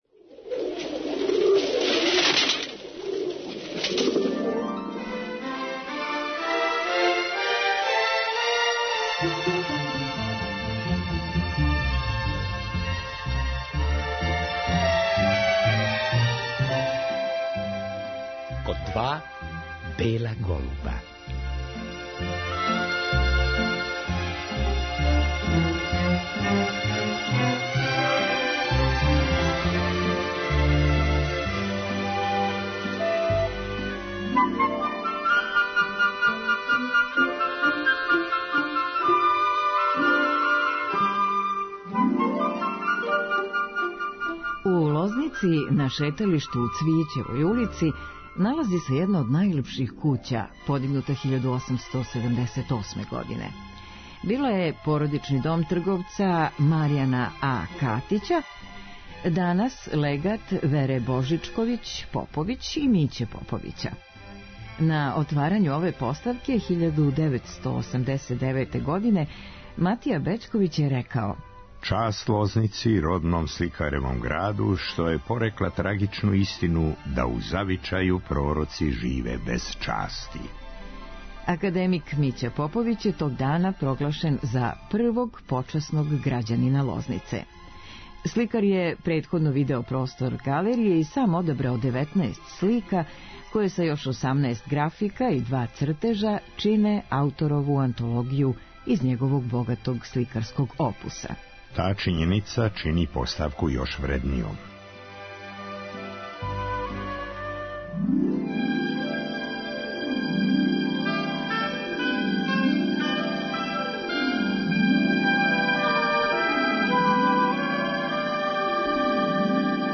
У вечерашњој емсији чућемо разговор са сликарем који је забележен 1991. године. Мића Поповић прича о свом животу и ликовном стваралаштву.